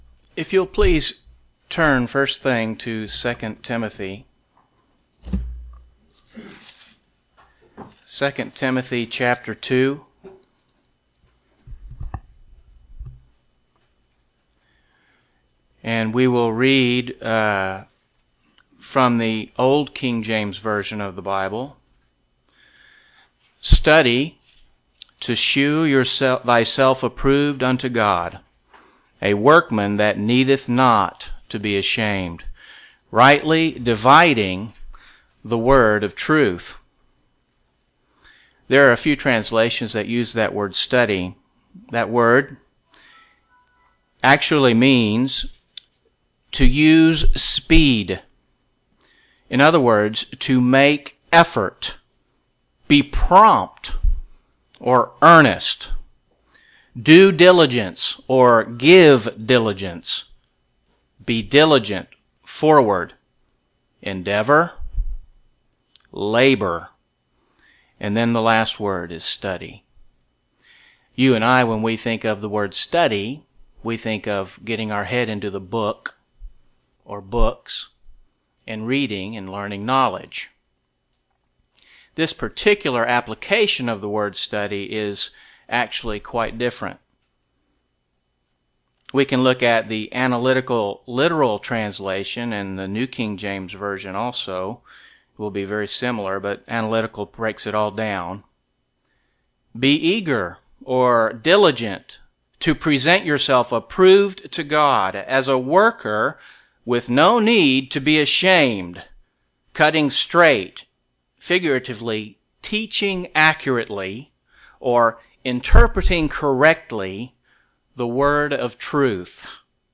UCG Sermon practice student Notes PRESENTER'S NOTES 2 Timothy 2:15 King James Bible (Cambridge Ed.)